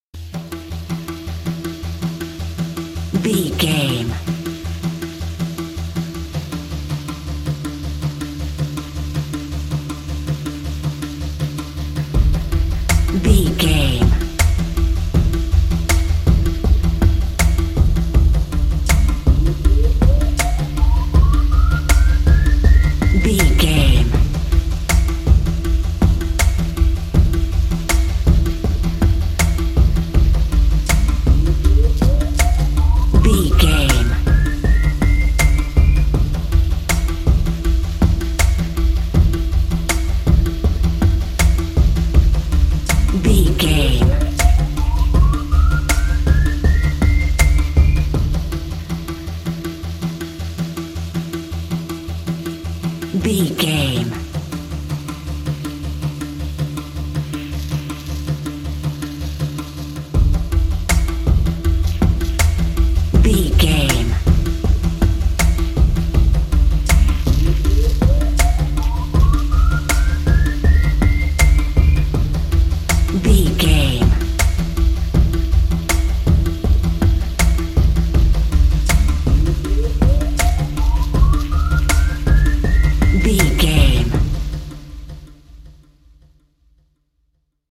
In-crescendo
Thriller
Aeolian/Minor
Slow
drum machine
synthesiser